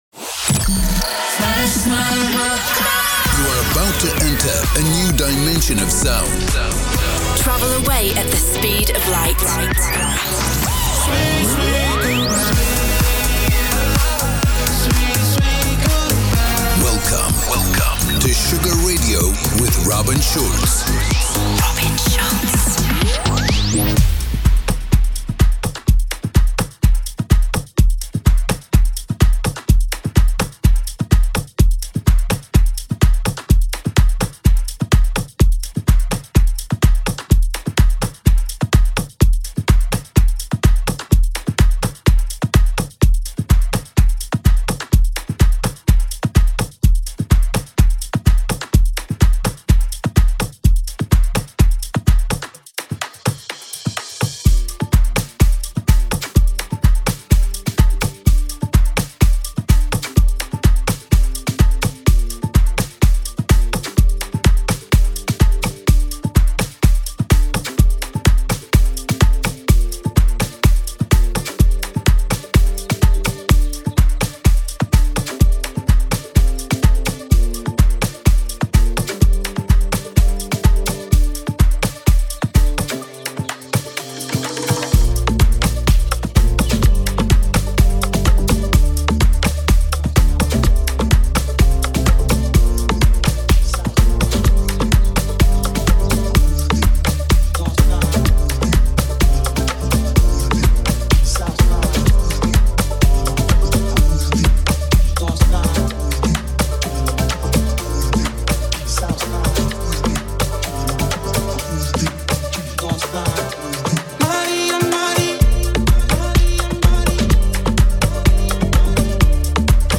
Uma hora com o melhor Tropical House do músico